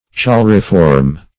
Choleriform \Chol"er*i*form`\, a.